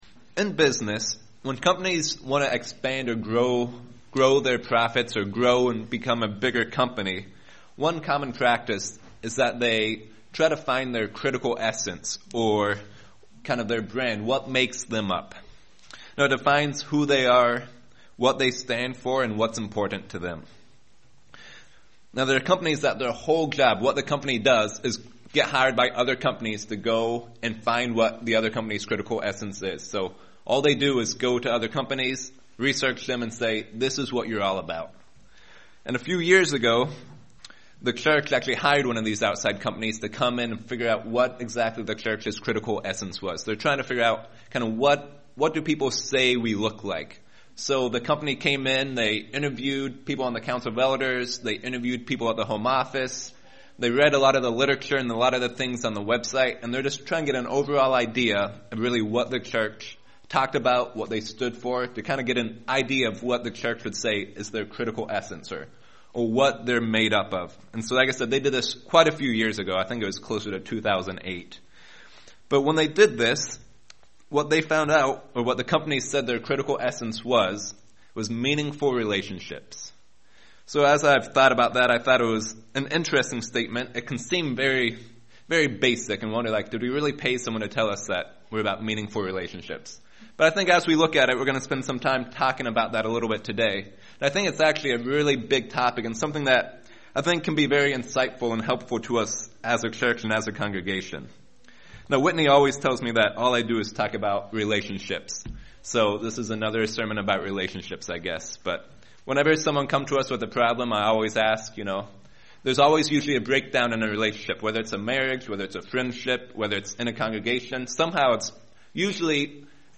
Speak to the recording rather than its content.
Given in Lehigh Valley, PA